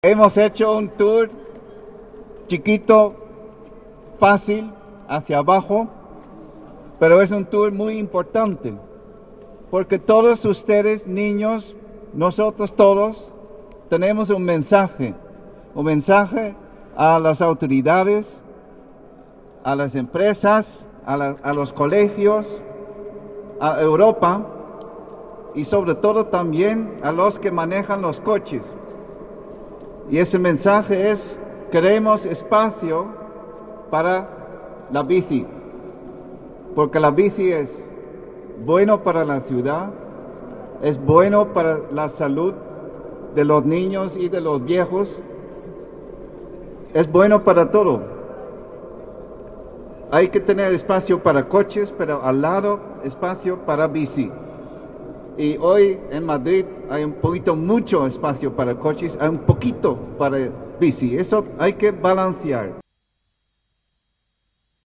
Nueva ventana:Matthijs van Bonzel, embajador de los Países Bajos
EmbajadorHolandaBicicletada-28-04.mp3